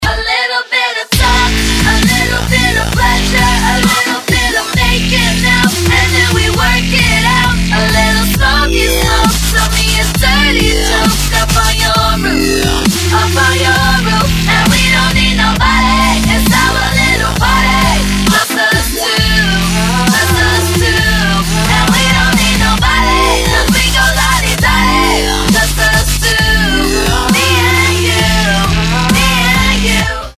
Клубные [95]